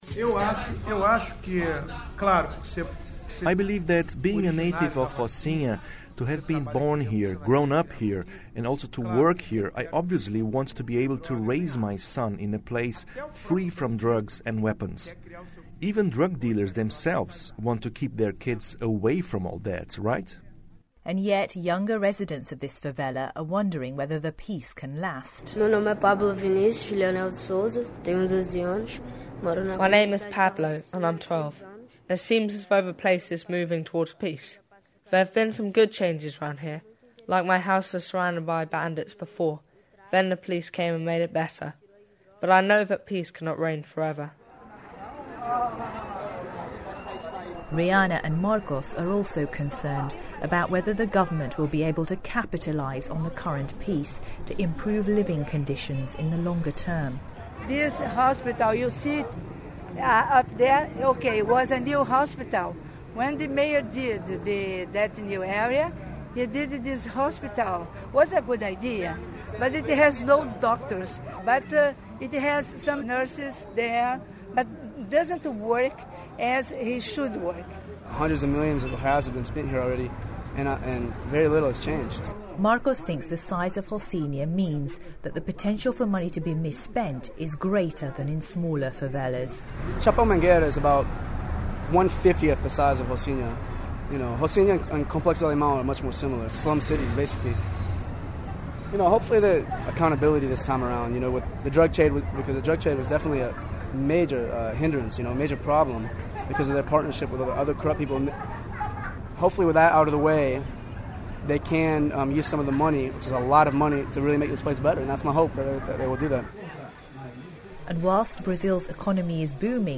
Broadcast on BBC World Service in 2012.